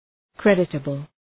Προφορά
{‘kredıtəbəl}
creditable.mp3